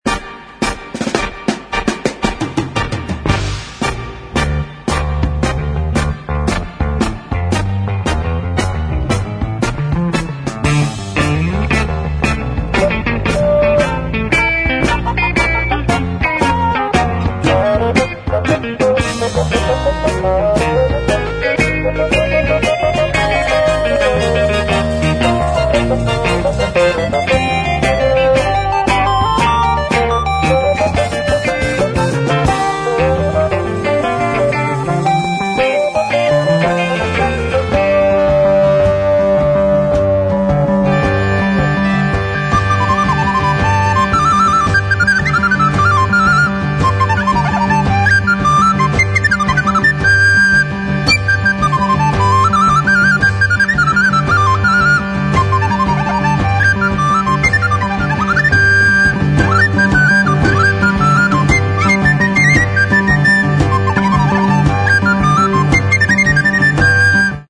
Mono, 1:07, 32 Khz, (file size: 264 Kb).